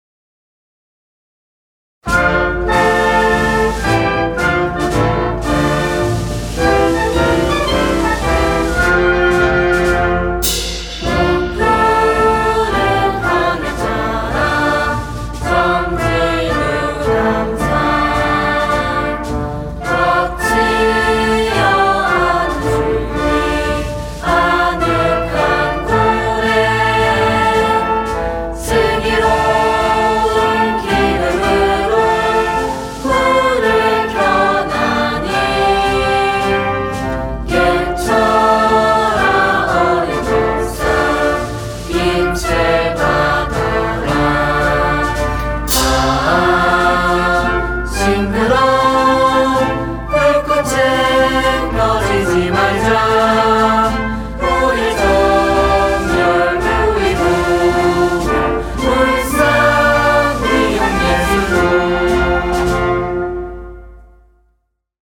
울산미용예술고등학교 교가 음원 :울산교육디지털박물관